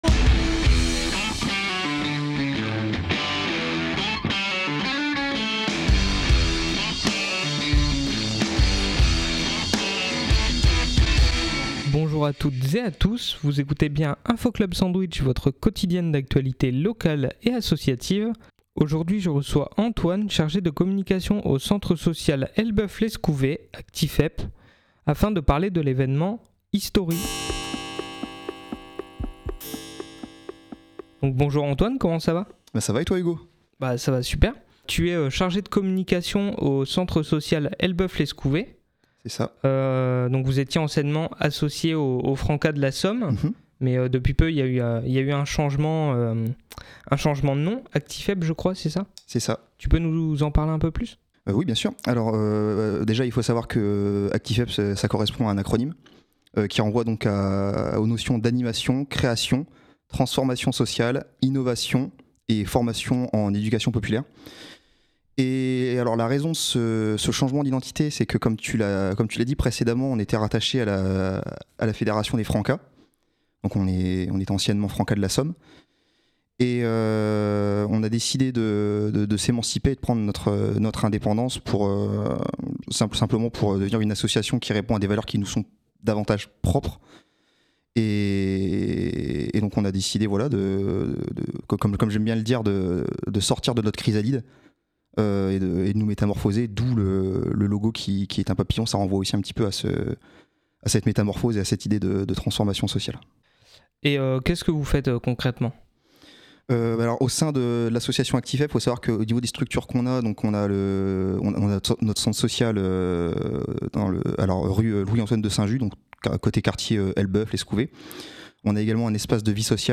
Cette interview est aussi l’occasion d’évoquer les évolutions du Centre Social.